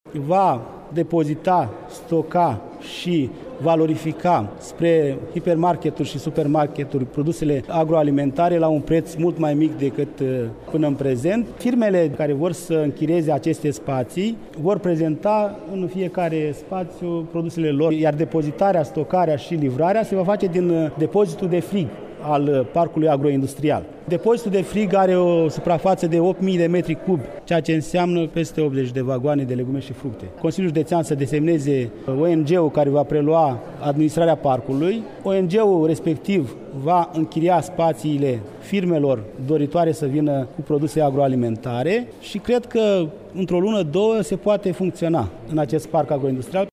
Primarul comunei Lecţani, Stelian Turcu: